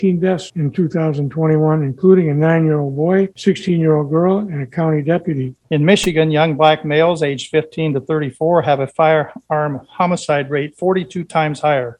Several spoke at last evening’s Kalamazoo County Board of Commissioners meeting, urging immediate action.